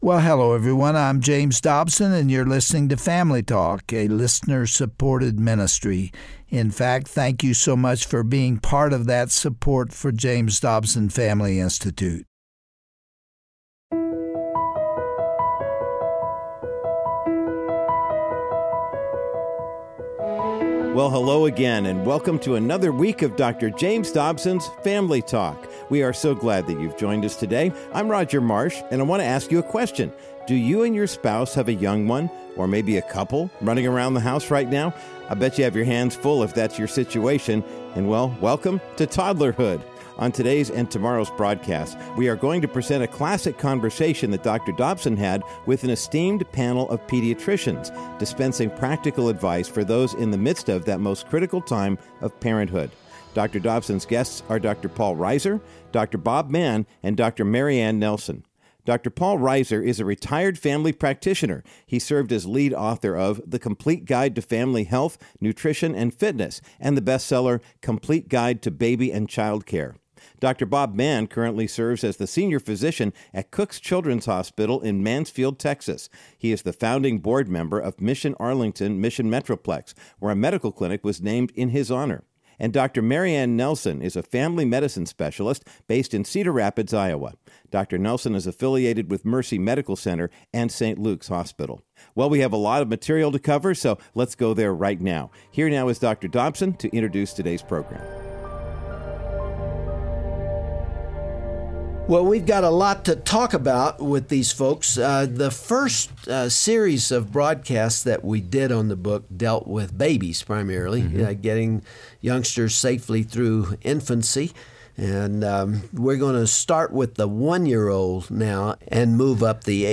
On today’s classic edition of Family Talk, Dr. James Dobson interviews a panel of doctors who insist that Mom and Dad lovingly let their toddler know that they are in charge. Listen to a valuable dose of encouragement and insight, and learn how to win the battle over bedtime and mealtime, the best toys for any age range, and the optimum number of hours of sleep a toddler needs per day.